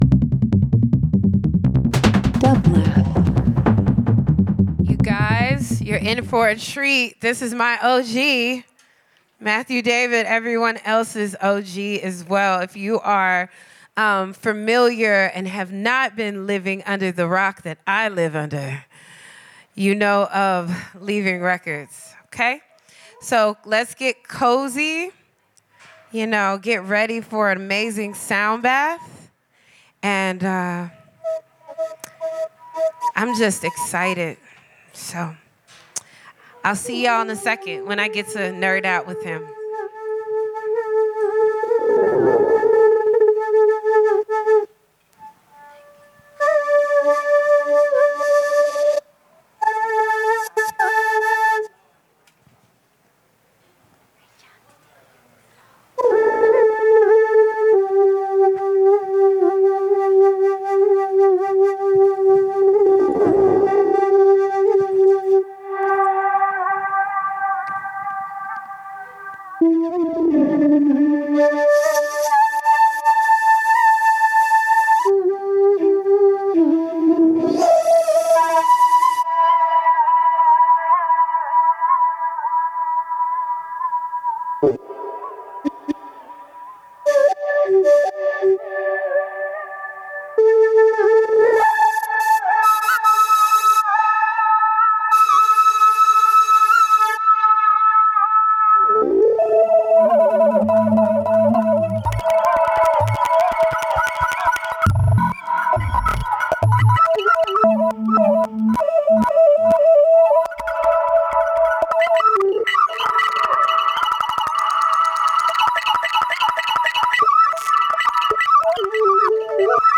LIVE FROM THE LOFT @ PORTER STREET STUDIO
Ambient Avant-Garde Electronic Experimental